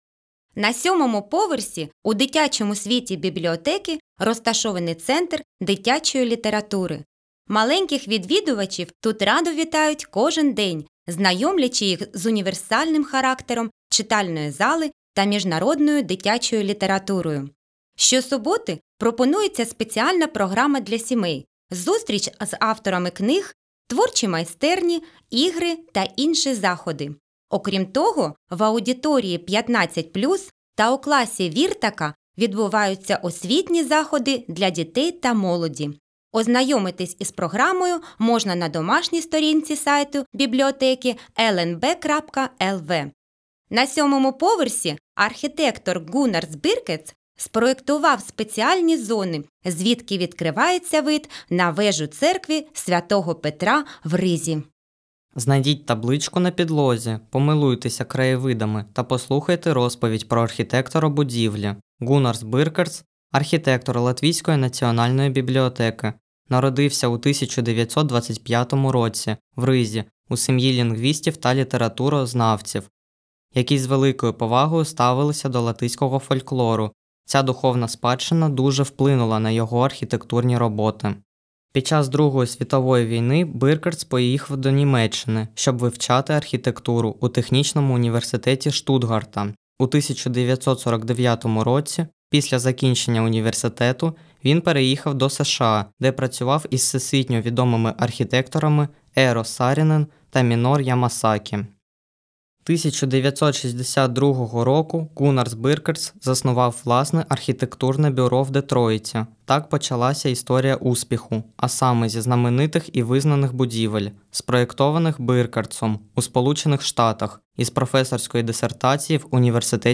Аудіогід Національної бібліотеки Латвії
balss aktieris
Tūrisma gidi
Latvijas Nacionālās bibliotēkas audio studijas ieraksti (Kolekcija)